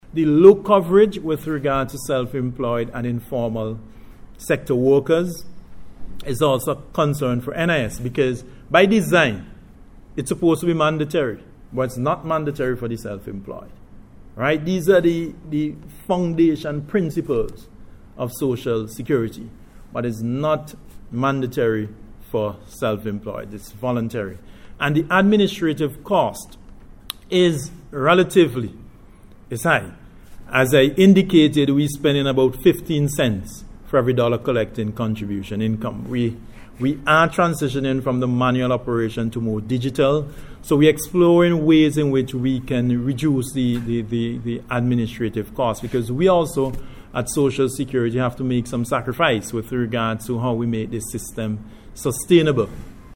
Director of the NIS Stewart Haynes reiterated this commitment, during a media briefing, hosted by the NIS yesterday to discuss the 11th Actuarial Report., which was produced by an independent Actuary.